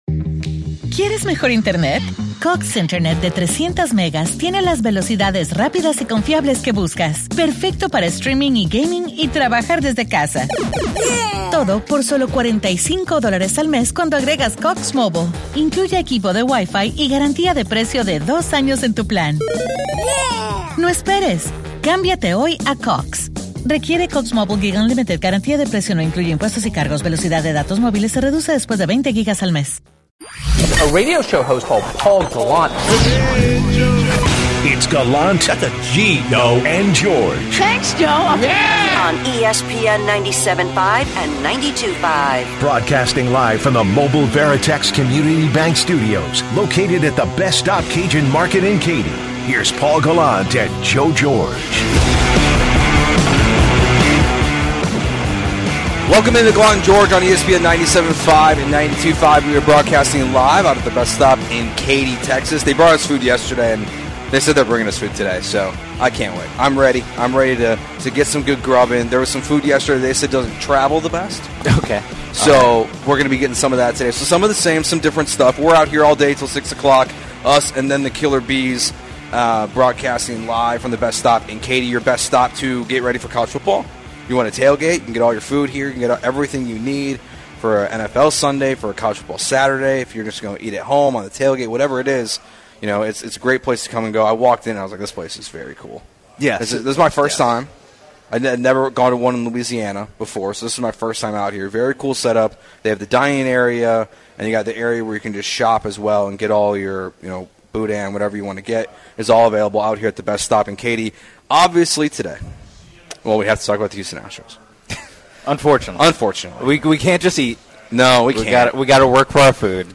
-Live in Katy! Road show!